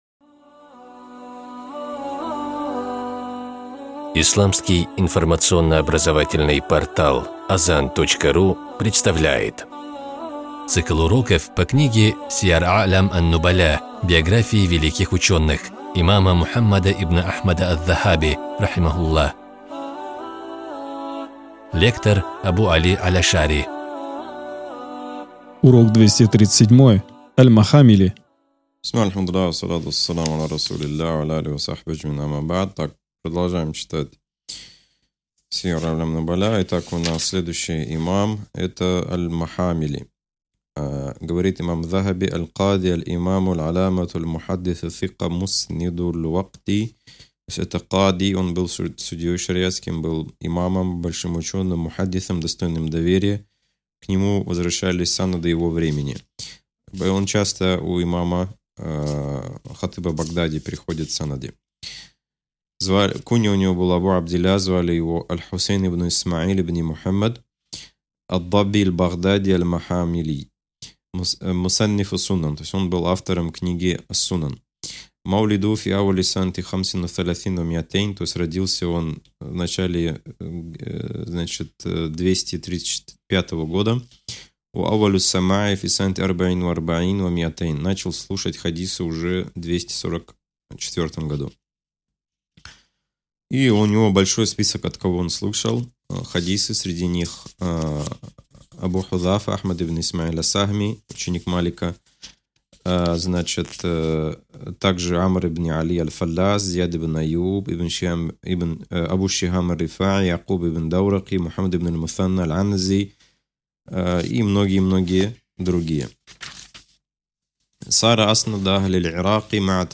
С позволения Всевышнего Аллаха, мы начинаем выкладывать аудио-уроки по книге имама Мухаммада ибн Ахмада Аз-Захаби, рахимахуллах, «Сияр а’лям ан-Нубаля» (биографии великих ученых).